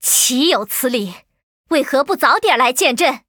文件 文件历史 文件用途 全域文件用途 Erze_amb_04.ogg （Ogg Vorbis声音文件，长度0.0秒，0 bps，文件大小：38 KB） 源地址:游戏语音 文件历史 点击某个日期/时间查看对应时刻的文件。